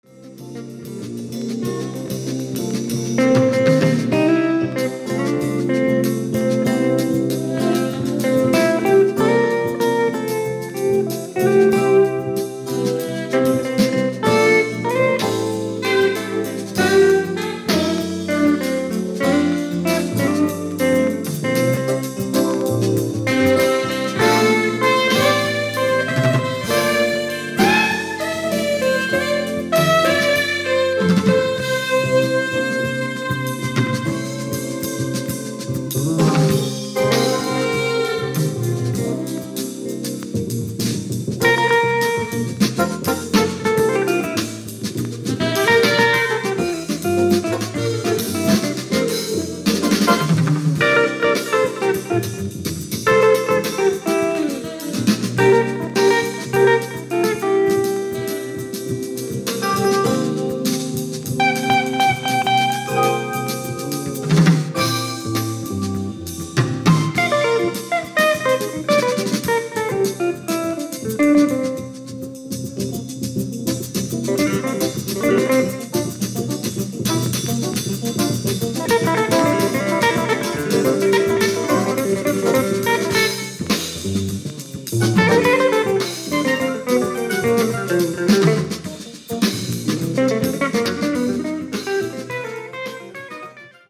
Country : Brazil
Jazz Fusion
軽快なサウンドがこれからの季節ピッタリですね。